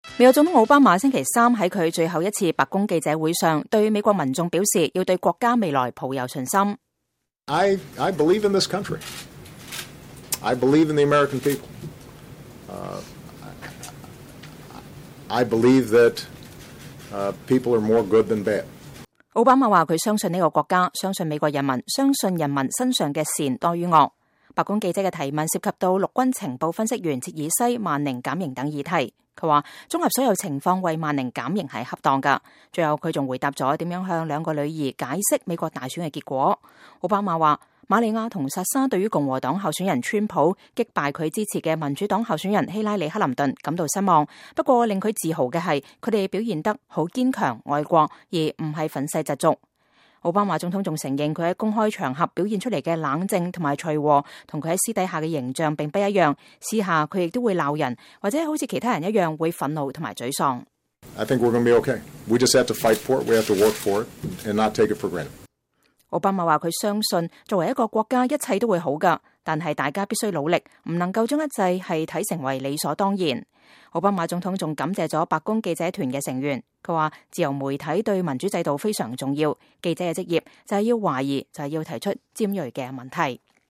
美國總統奧巴馬星期三在他最後一次白宮記者會上對美國民眾表示要對國家未來抱有信心。